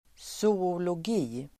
Ladda ner uttalet
zoologi substantiv, zoology Uttal: [s(o:)olåg'i:] Böjningar: zoologien Definition: vetenskapen om djuren Zoology substantiv (utbildning), zoologi Förklaring: The scientific study of animals and the way they behave.